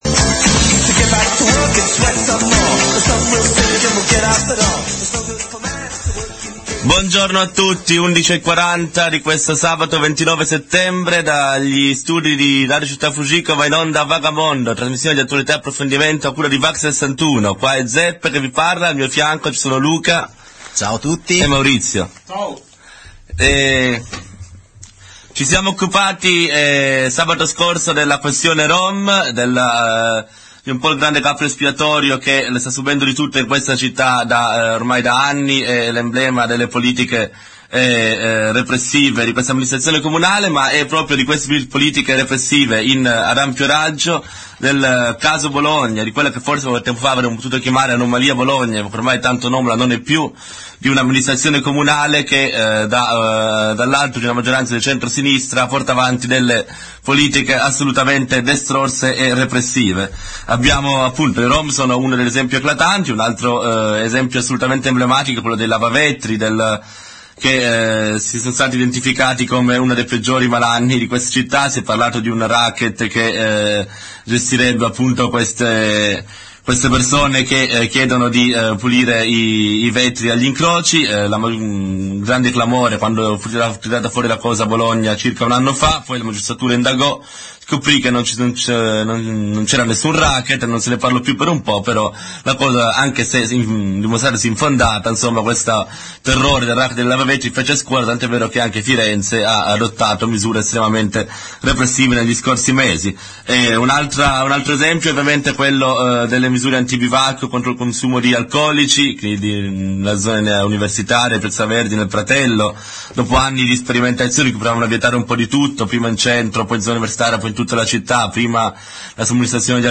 Vag61 - Scarica la puntata del 29 settembre '07 - Rom, lavavatri, writers, spazi autogestiti, libera socialità: puntata dedicata alle politiche securitarie (in esportazione...) con cui l'amministrazione Cofferati sta costruendo un modello di città basato sull'esclusione e la normalizzazione. Interviste